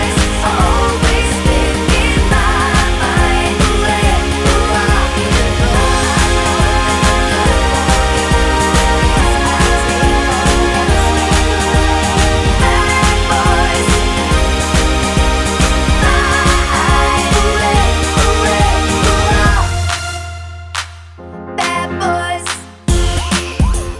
No Rapper Pop (2010s) 3:27 Buy £1.50